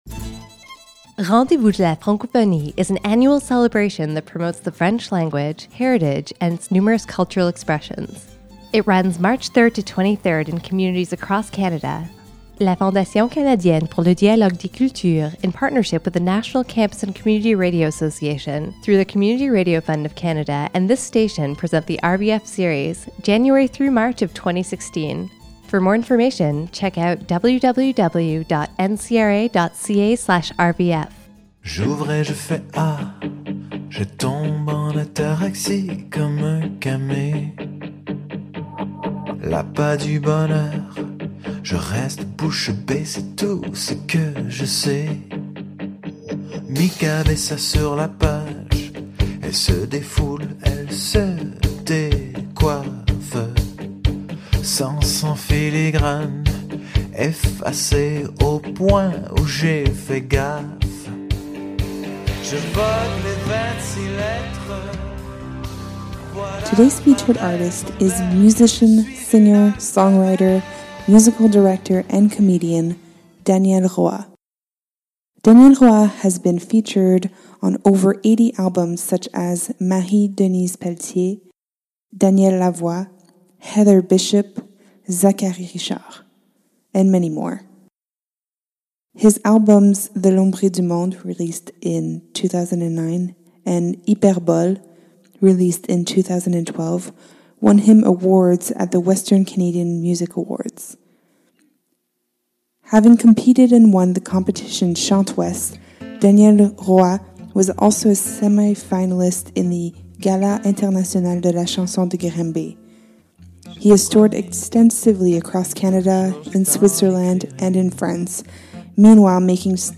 Capsules containing information, interviews, and music from different Western Canadian Francophone artists.